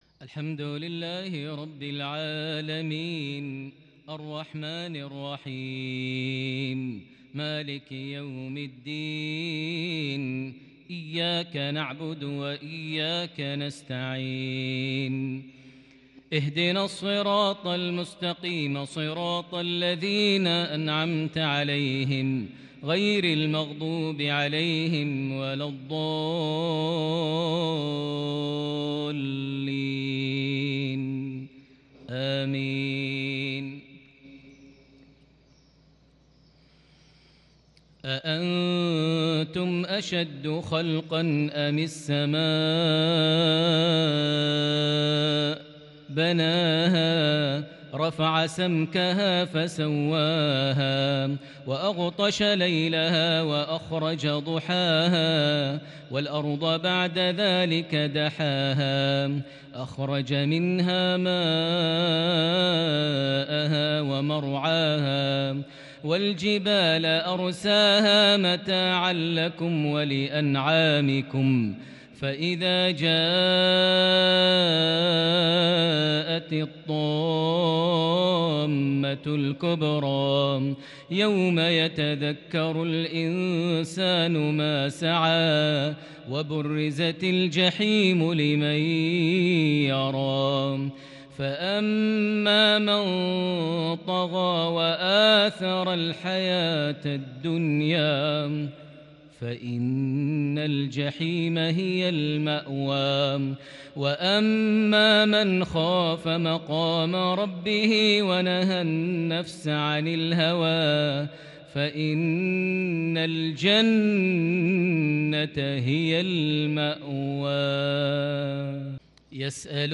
(فإن الجنة هي المأوى) مغربية شجية لخواتيم سورة النازعات (27-46) | 27 ربيع الأول 1442هـ > 1442 هـ > الفروض - تلاوات ماهر المعيقلي